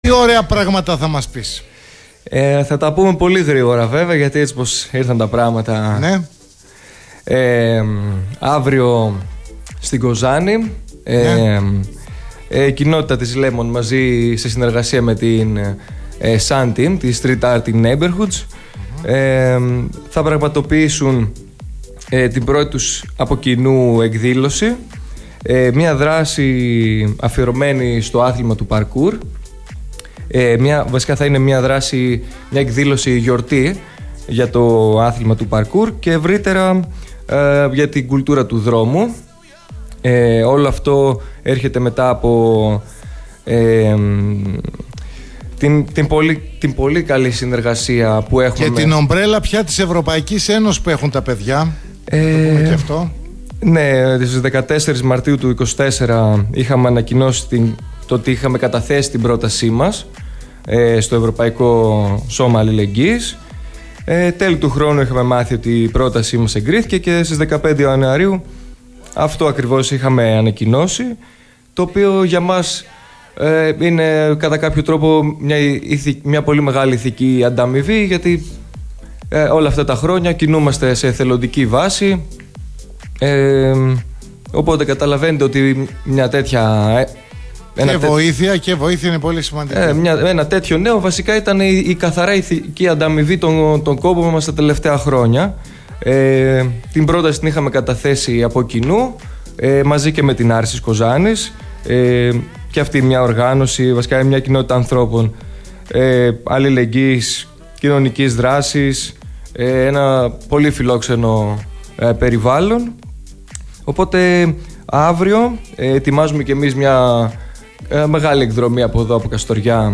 PARKOUR DAY: Η πρώτη κοινή εκδήλωση των Lemon Skates και SAN Team αύριο στην Κοζάνη – Συνέντευξη